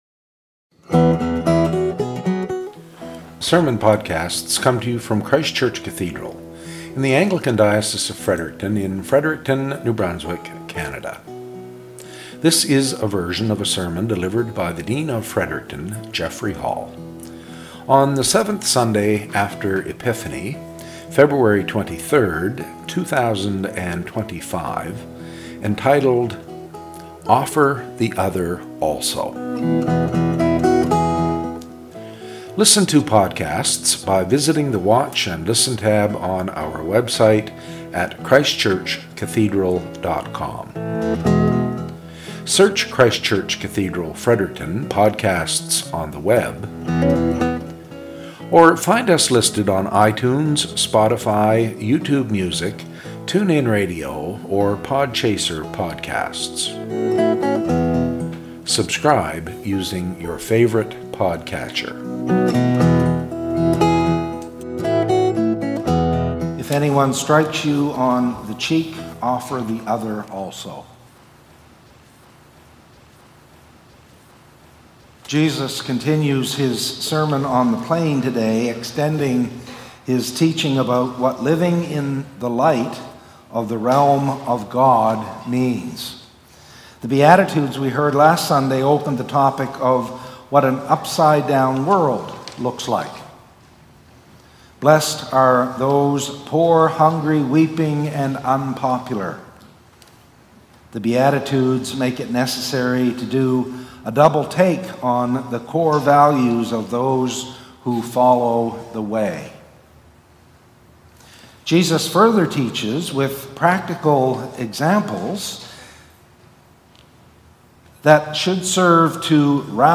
SERMON - "Offer the Other Also"